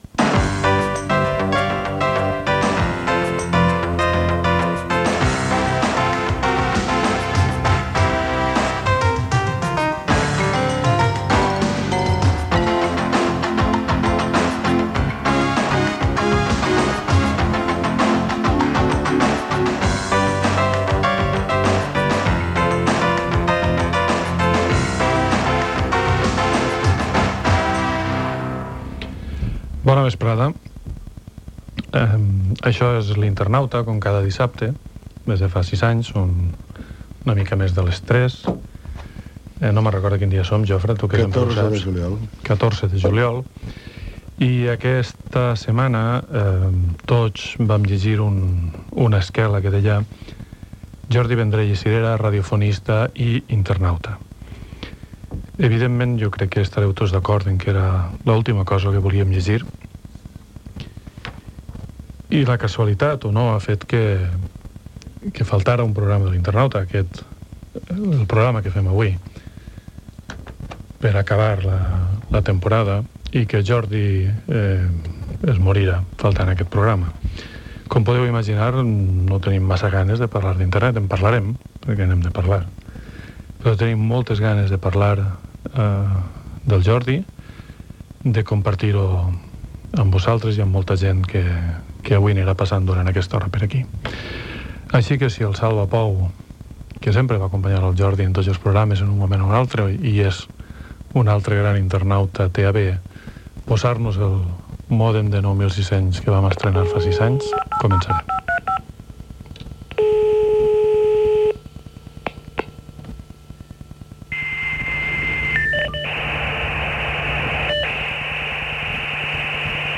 1b26f8d85a4cc49983abaac34758efbafeac4a99.mp3 Títol Catalunya Ràdio Emissora Catalunya Ràdio Cadena Catalunya Ràdio Titularitat Pública nacional Nom programa L'internauta Descripció Sintonia de l'emissora.